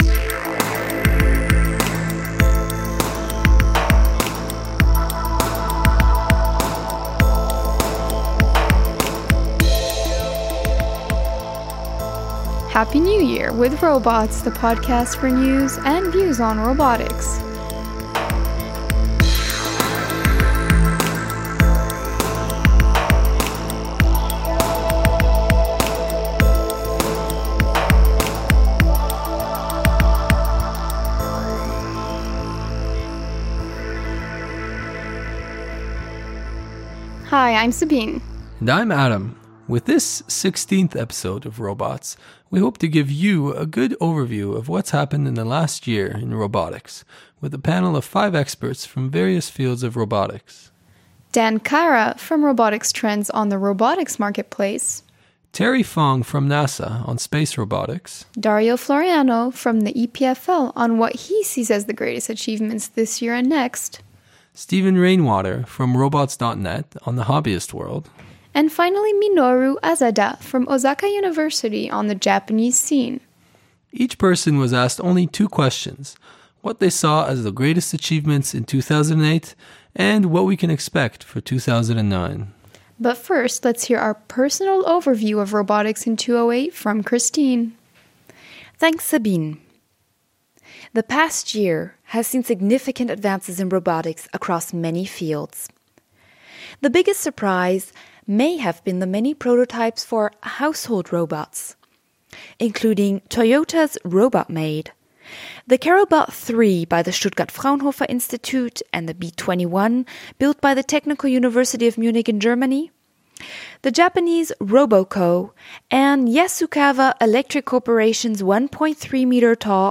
For our New Year’s episode, we’ll be giving you an overview of the trends in robotics for 2008 and an insight into next year’s developments with five experts in robotics from different backgrounds and continents.